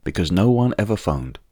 Hi, here are some samples of the final ‘d’, ‘t’ etc. sounds disappearing or becoming very faint after using de-clicker.